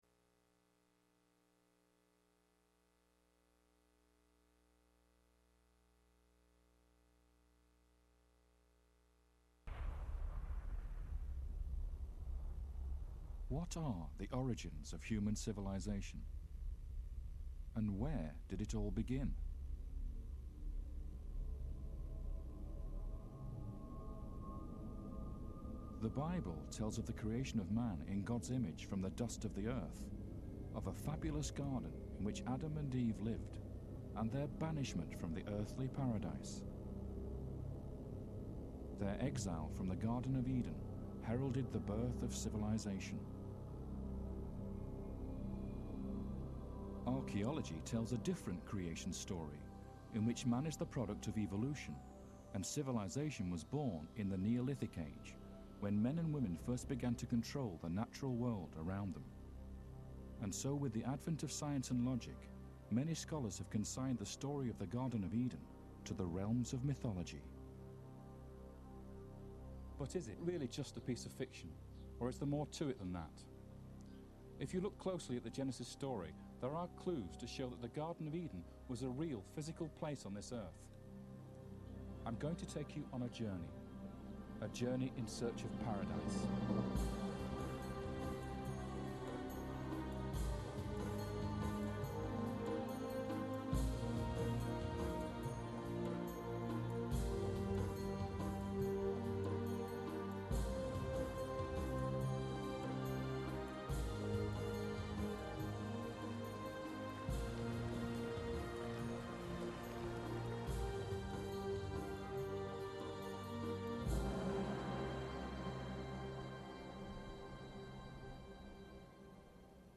In Search of Eden is a documentary on an agnoostic as he found Eden's location.